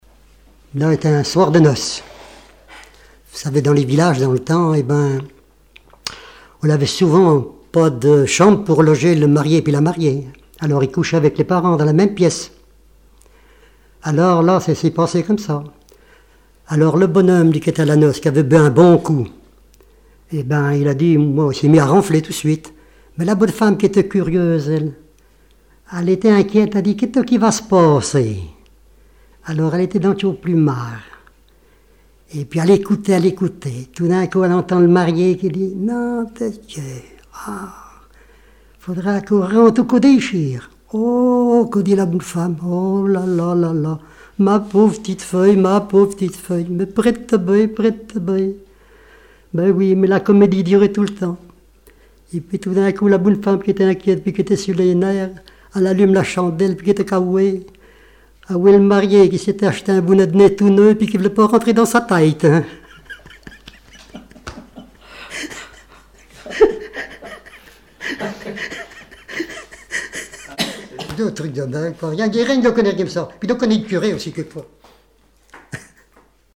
regroupement de chanteurs locaux
Catégorie Témoignage